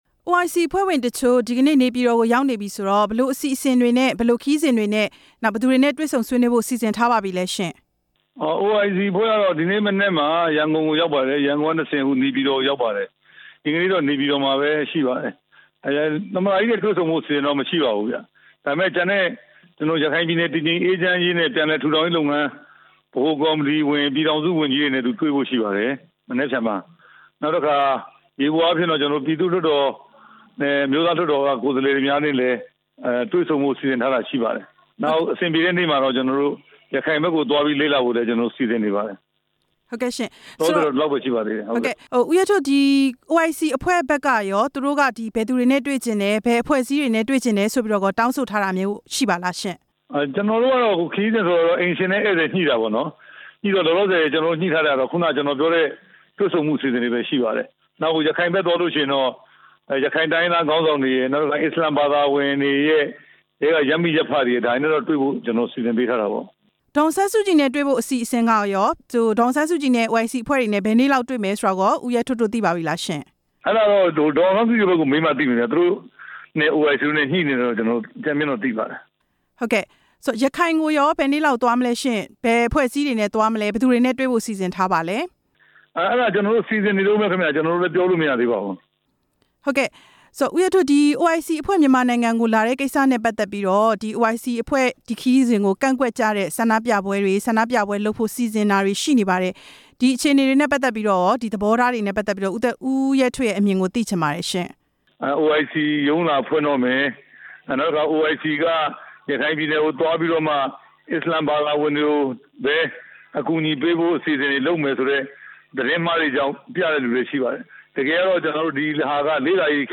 ပြန်ကြားရေးဒုဝန်ကြီး ဦးရဲထွဋ်နဲ့ မေးမြန်းချက်